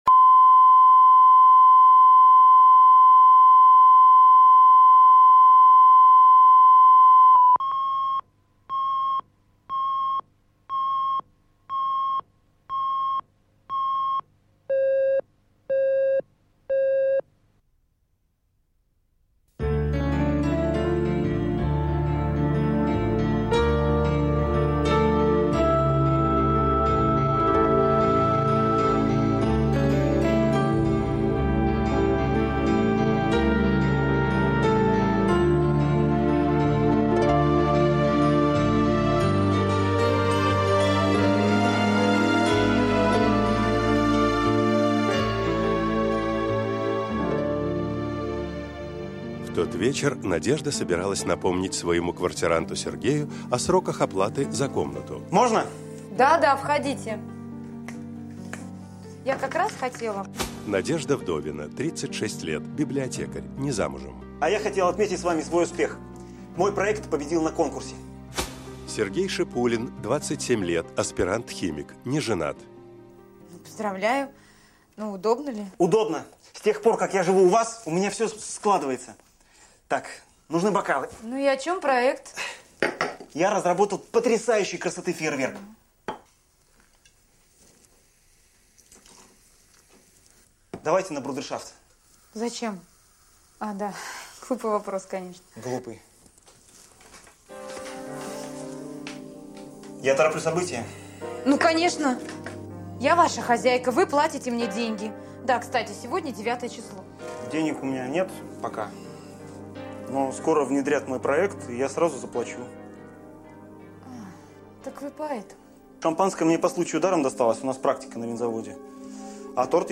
Аудиокнига Смешанные чувства | Библиотека аудиокниг
Прослушать и бесплатно скачать фрагмент аудиокниги